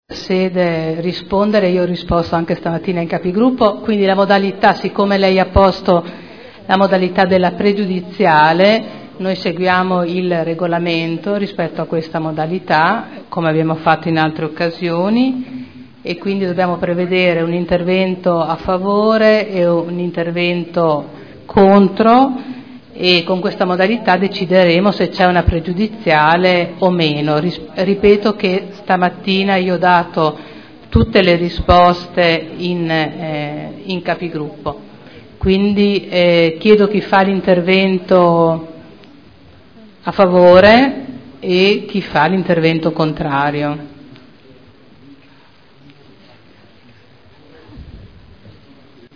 Seduta del 26/11/2012 risponde a pregiudiziale della Consigliera Vecchi.